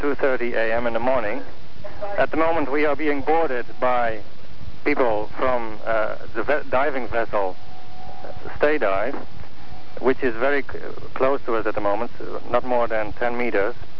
Transcripts of telephone call.
From another Greenpeace Protestor: